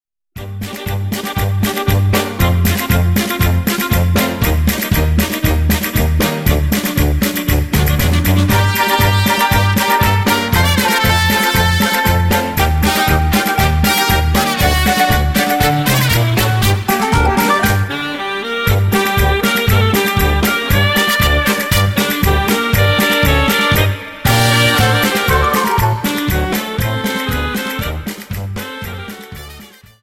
Dance: Paso Doble 59